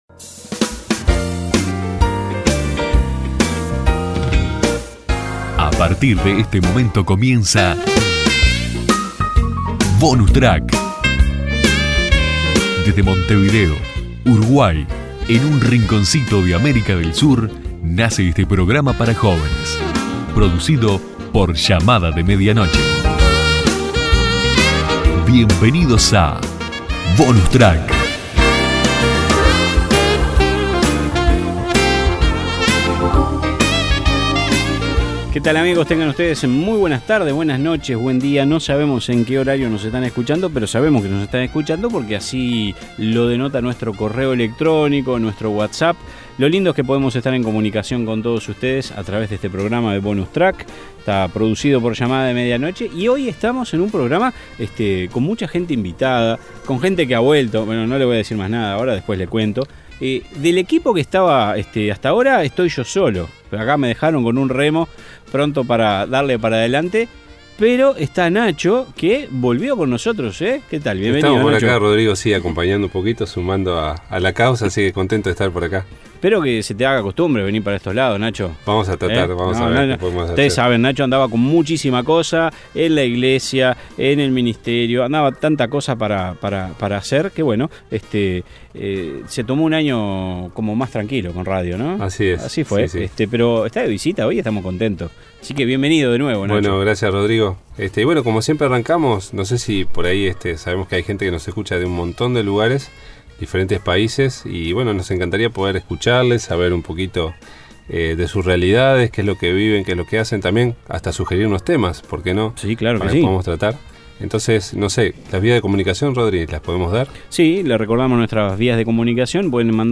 Bonus Track, te invita a que te prendas en sintonía durante 28 minutos para compartir un poco de todo: opiniones, invitados, un buen tema de conversación y la buena música, que no debe faltar.